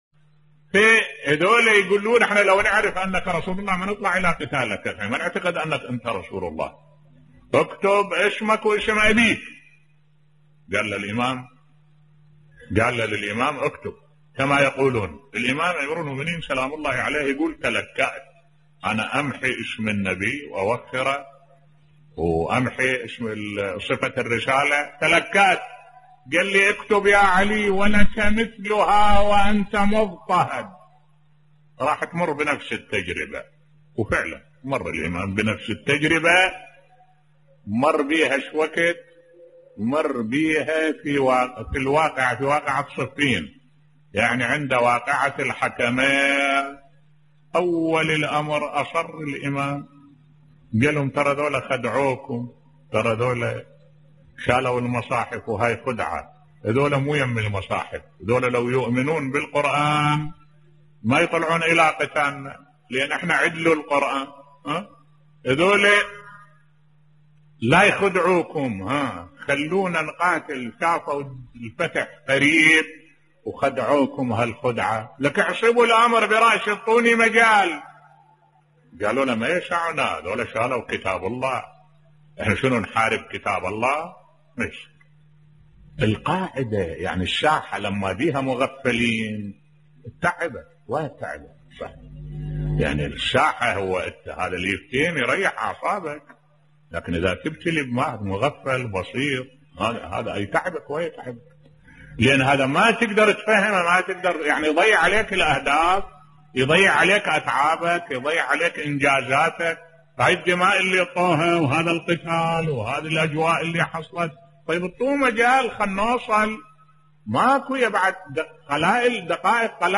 ملف صوتی التحكيم في صفين بصوت الشيخ الدكتور أحمد الوائلي